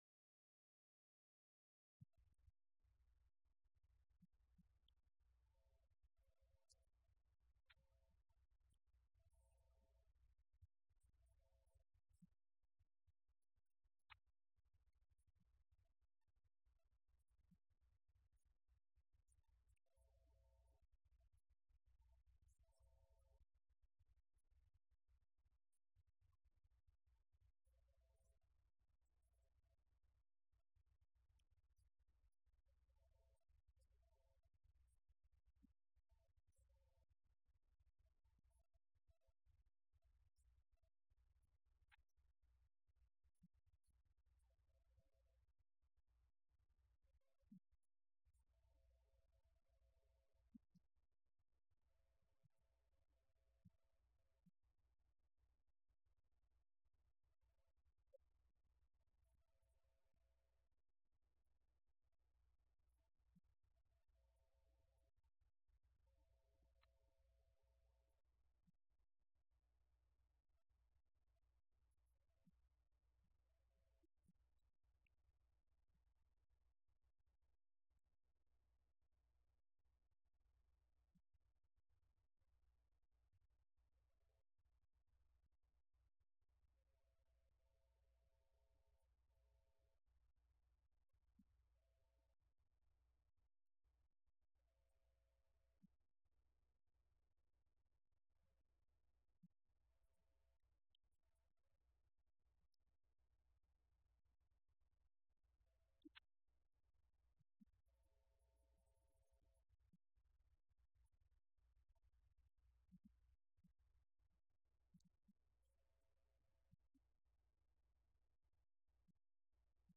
Event: 17th Annual Schertz Lectures
lecture